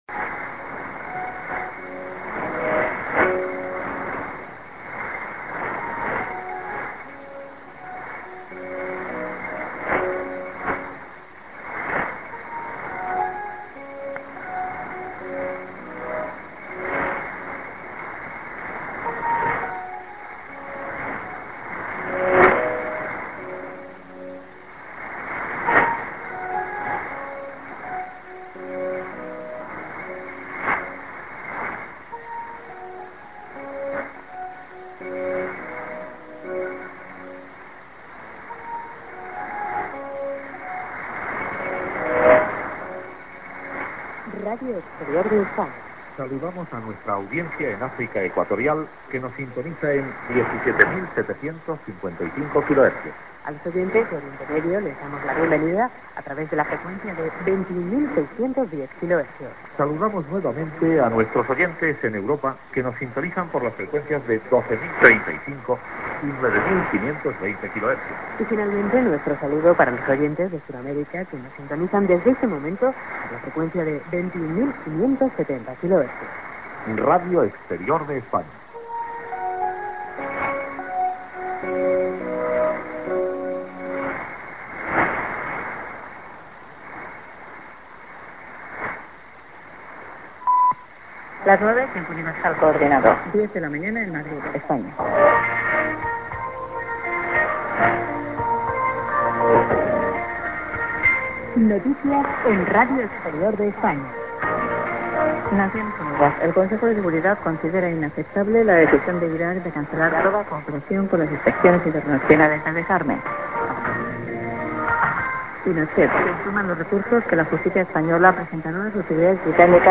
St. IS->ID(man+women)->IS->1'30":TS->ID(women)->ID(man)